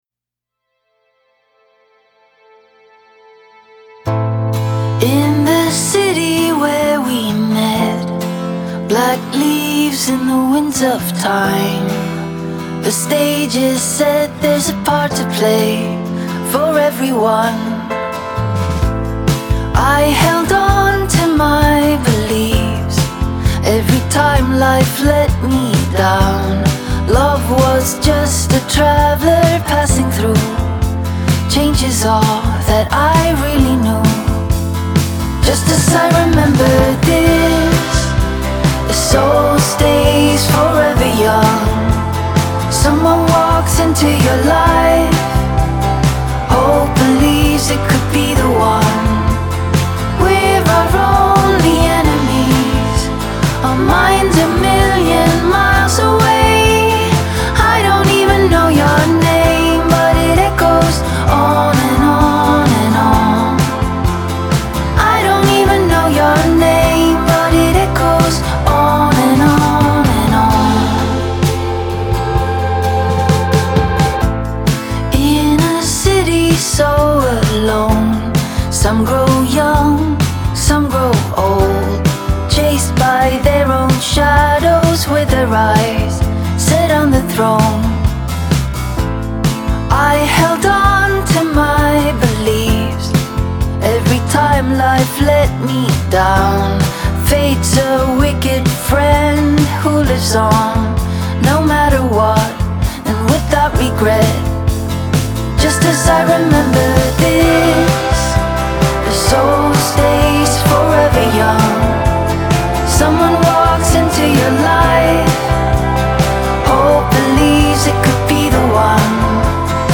Genre : Singer & Songwriter